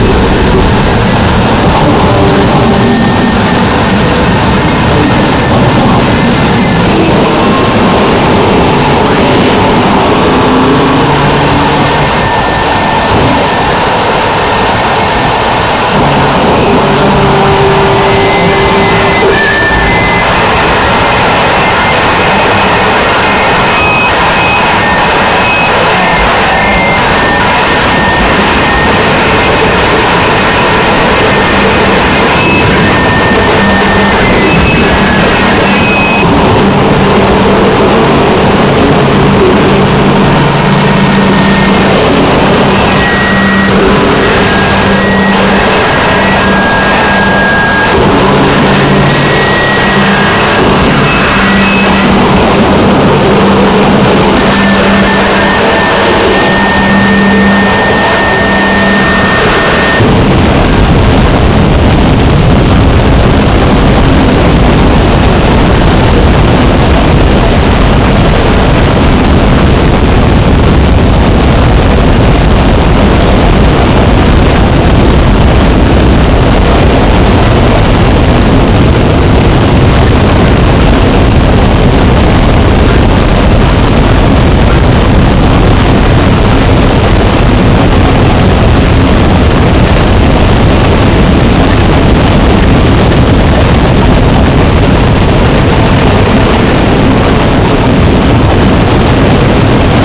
Harshness 8.6